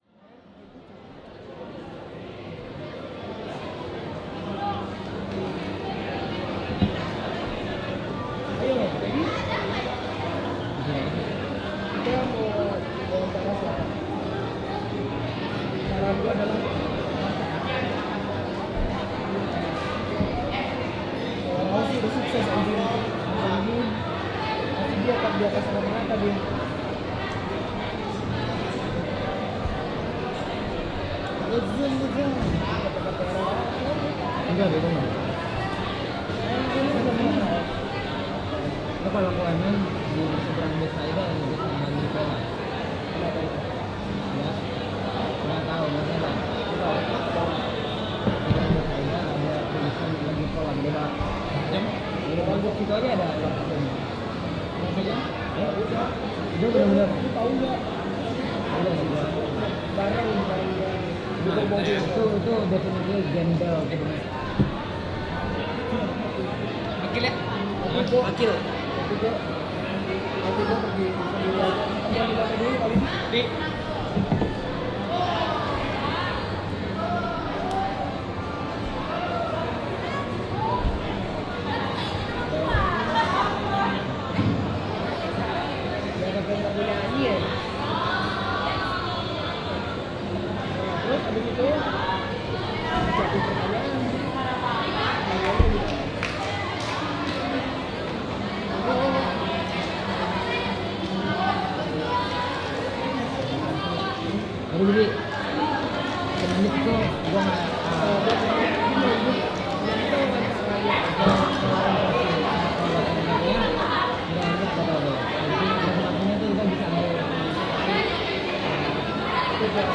描述：怨言不舒服的女人
Tag: 女人 不适 口中念念有词